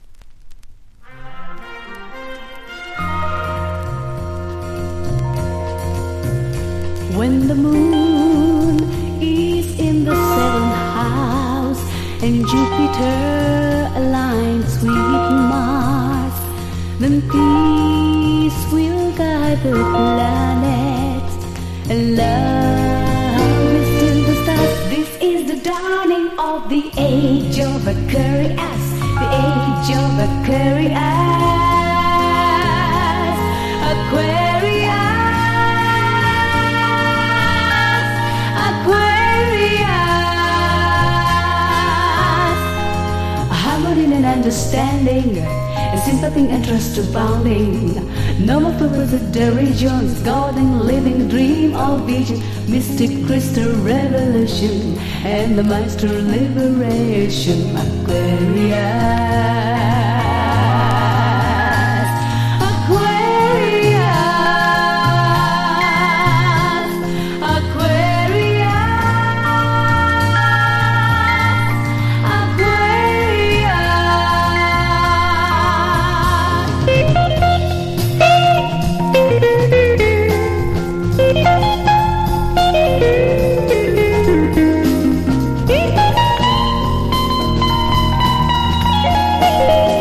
当時のポップスをJAZZアレンジで歌った人気盤。
POP